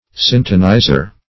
Syntonizer \Syn"to*niz`er\, n.